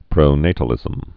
(prō-nātl-ĭzəm)